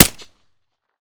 Decay/sound/weapons/arccw_ud/glock/fire-40-sup-04.ogg at 5c1ce5c4e269838d7f7c7d5a2b98015d2ace9247
fire-40-sup-04.ogg